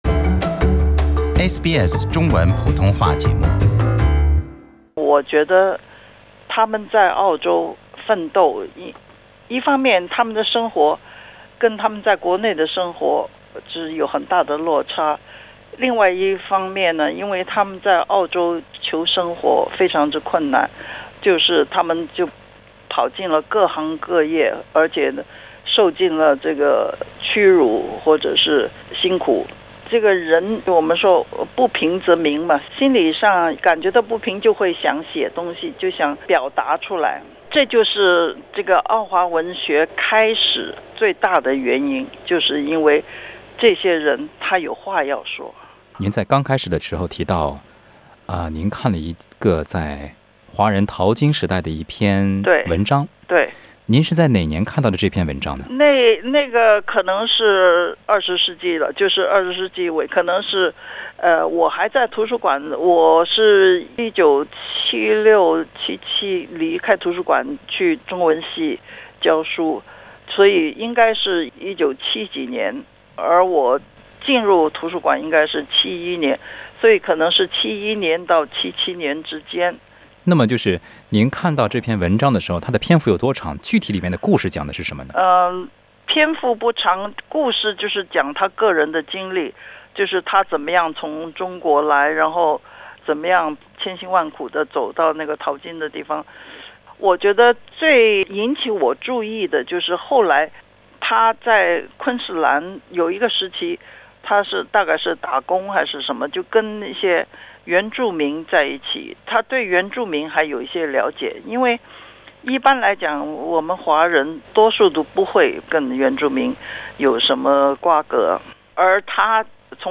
接下来请收听详细的采访内容